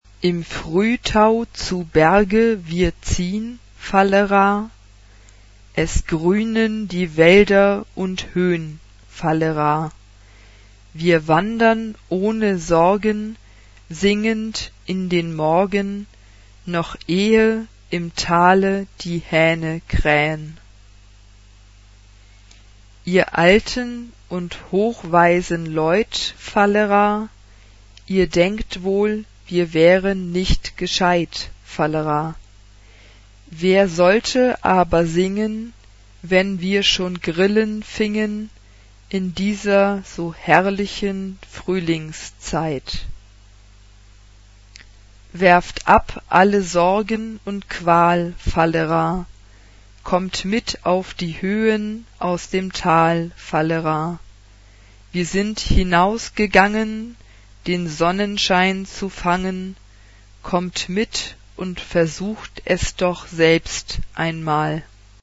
Partsong Mood of the piece: joyous Type of Choir: SA OR TB OR 2-part mixed OR SAA OR TBB OR SAB (2 women OR men OR mixed voices ) Instrumentation: Piano (1 instrumental part(s))
Tonality: F major